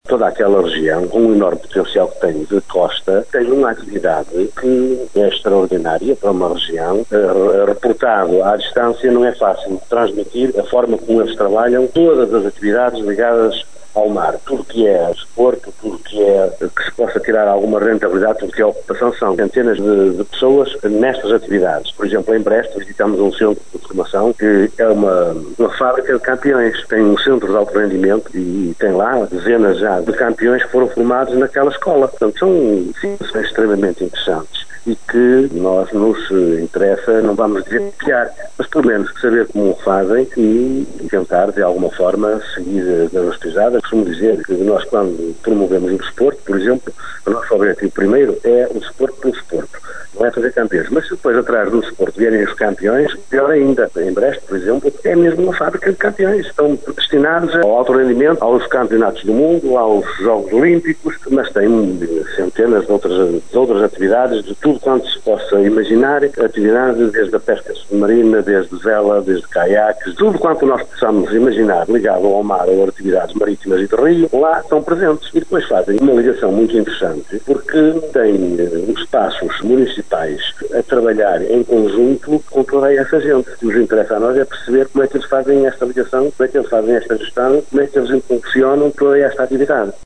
Viana do Castelo, Caminha e Vila Nova de Cerveira querem imitar o sucesso gaulês e aplicar o conceito no Alto Minho, como explicou à Rádio Caminha o autarca cerveirense, Fernando Nogueira.